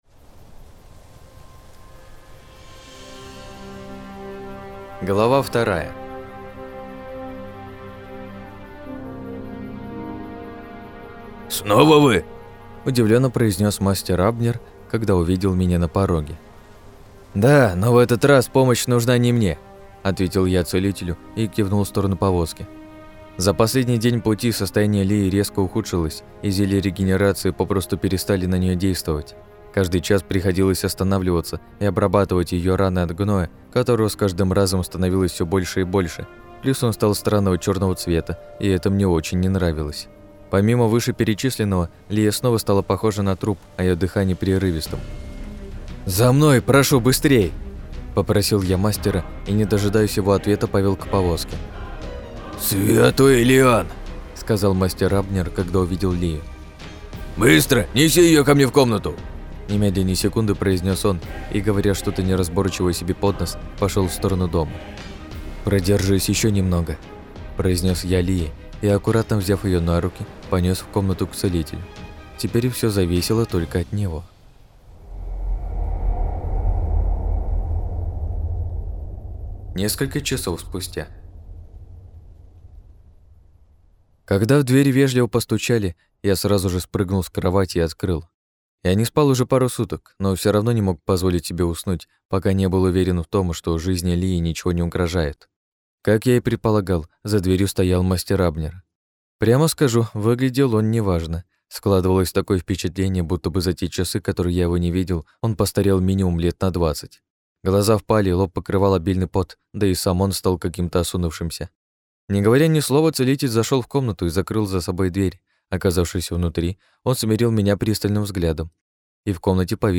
Аудиокнига - слушать онлайн